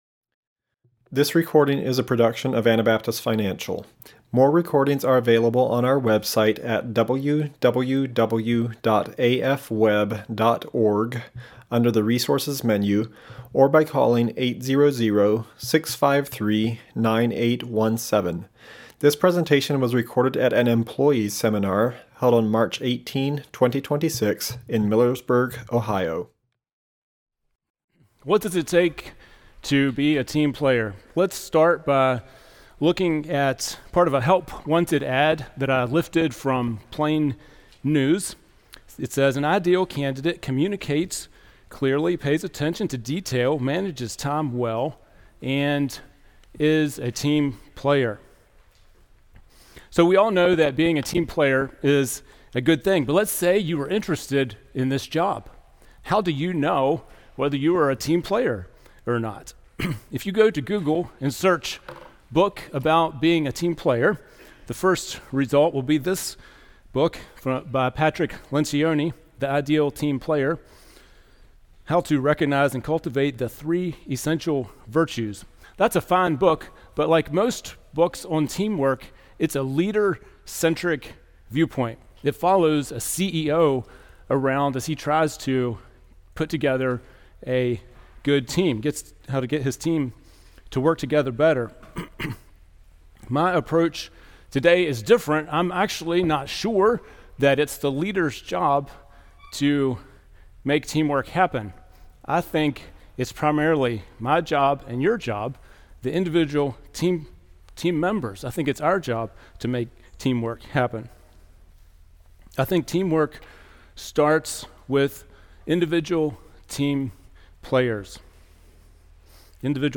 Ohio Employee Seminar 2026